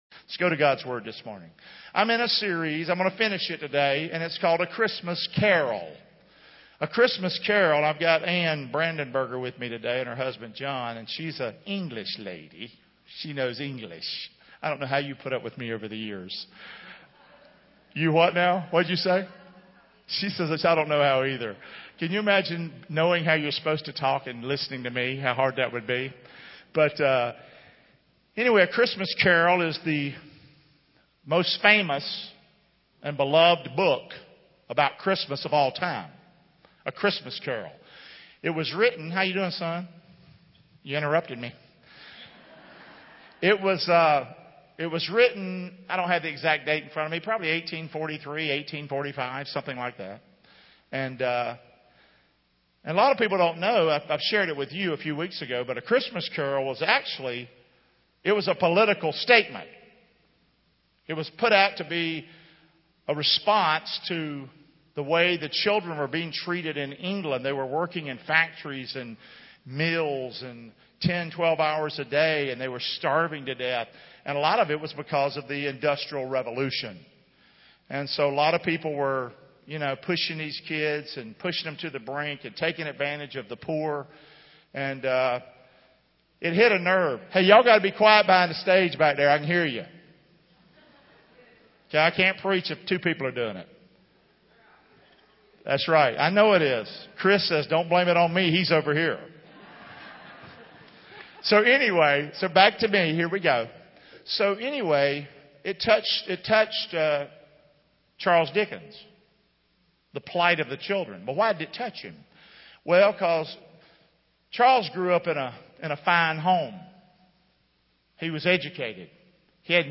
The latest sermons of Fellowship Church in Englewood, FL.